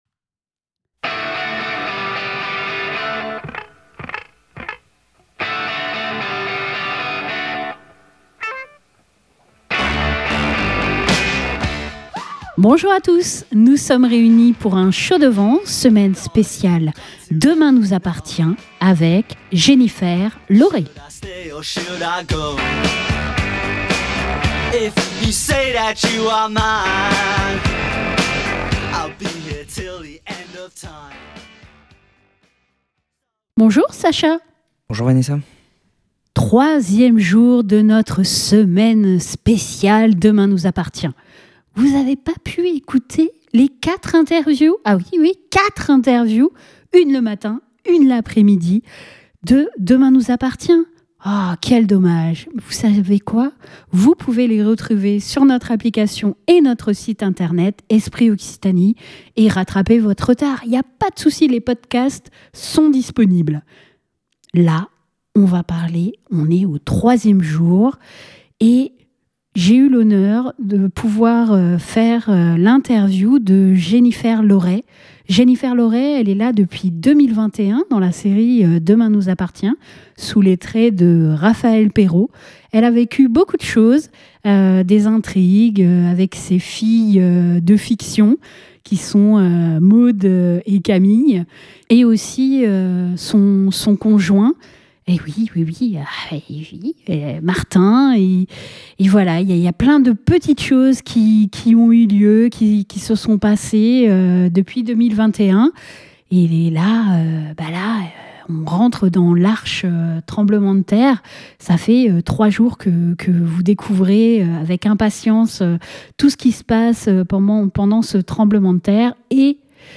Pour ce 3eme jour de notre semaine spéciale de la série "Demain nous appartient", nous sommes reçu par la comédienne Jennifer Lauret dans le cabinet de son personnage Raphaëlle Perraud. Et vous verrez qu'une interview peut rencontrer des péripéties tout comme le coeur de mère de Raphaëlle face à l'incertitude et l'inquiétude sur l'état de santé de sa fille Maud (Sixtine Dutheil) après le séisme et l'arrivée de sa cousine Marguerite Perraud (Lola Dubini).